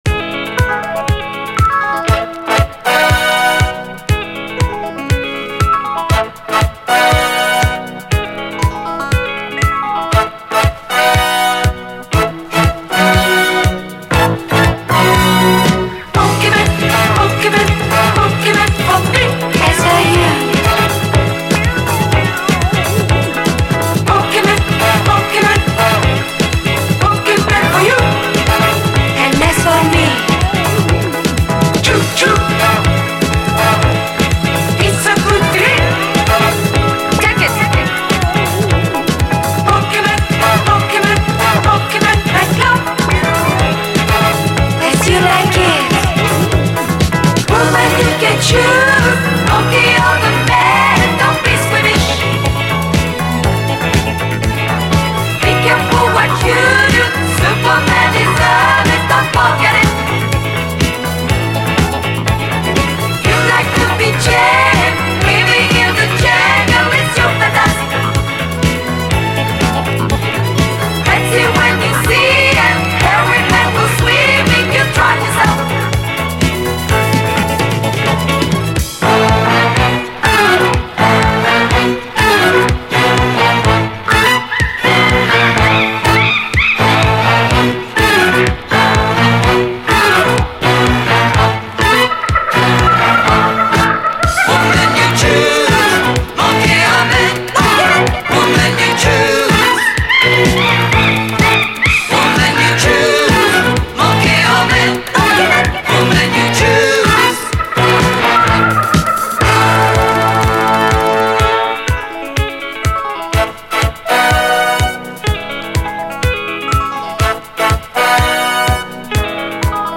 SOUL, 70's～ SOUL, DISCO
このキャッチーかつエロティックな妖しさはフレンチ・ディスコならでは、という感じ。